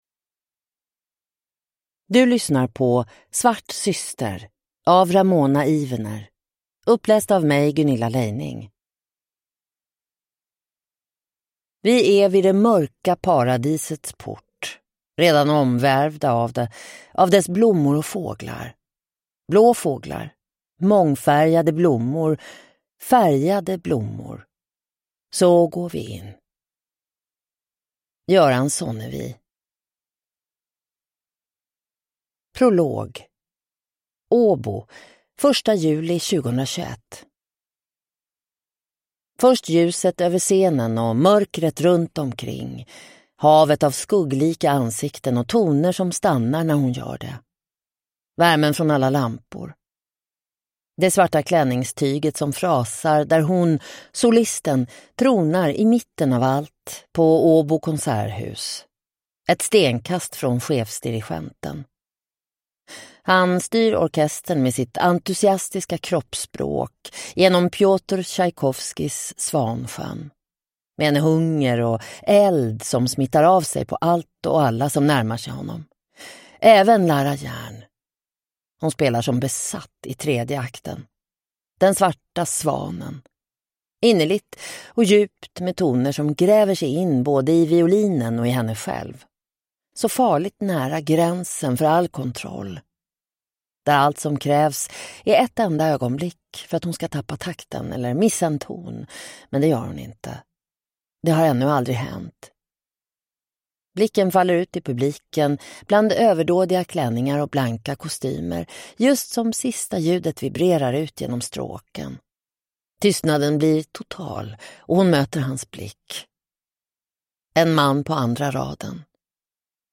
Svart syster (ljudbok) av Ramona Ivener